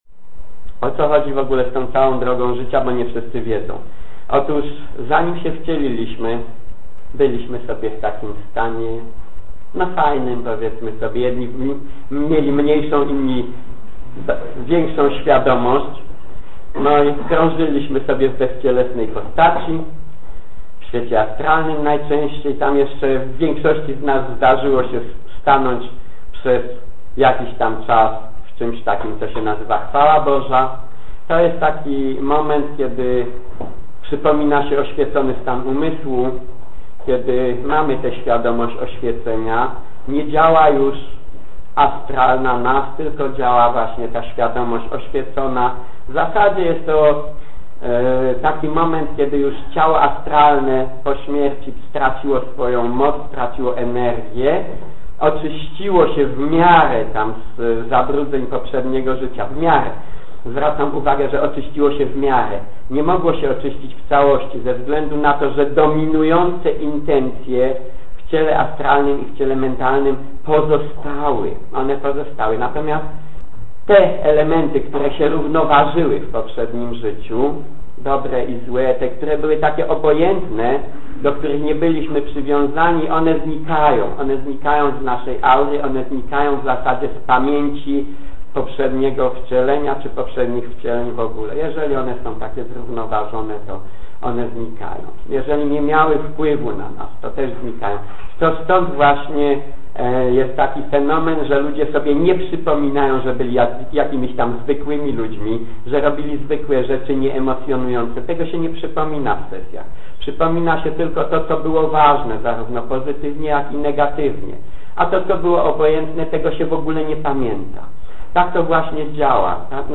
P�yta zawiera wyk�ady z Rocznej Szko�y Regresingu (RSR) w formacie MP3.